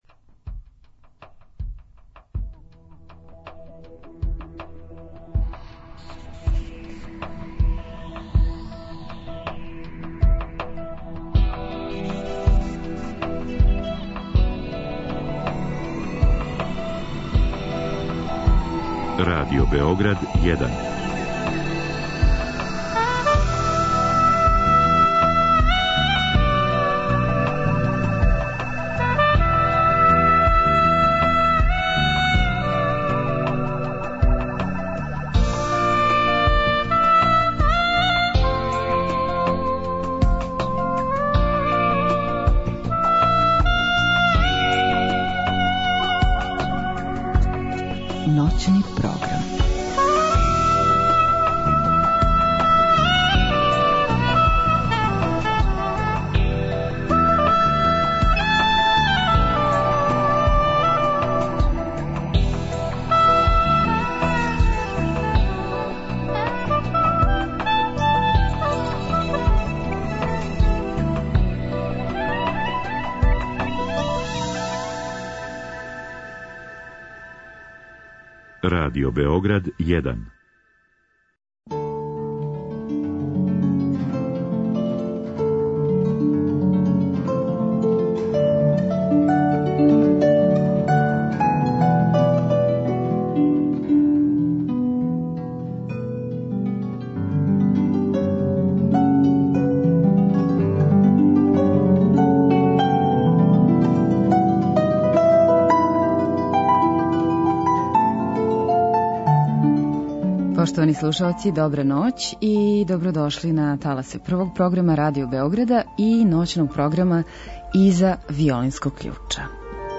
У ноћи среде на четвртак од 00.05 уживаћемо у звуцима клавирске и композицијама камерне музике.